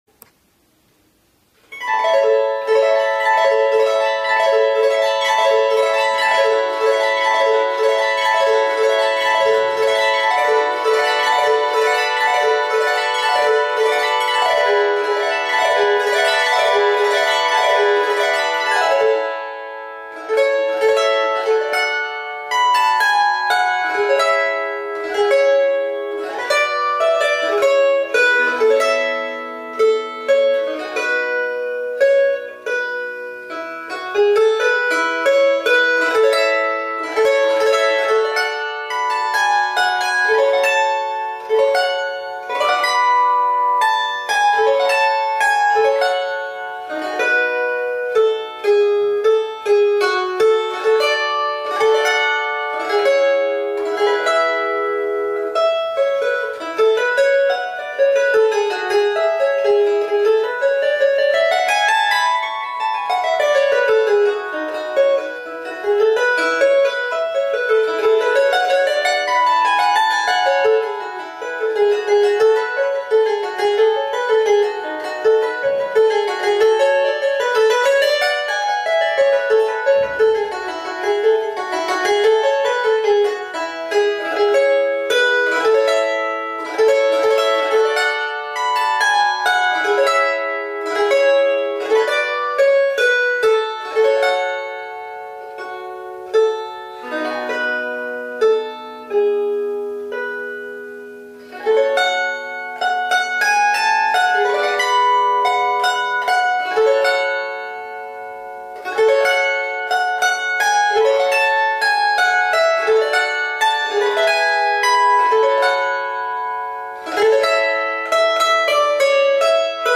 Как звучат гусли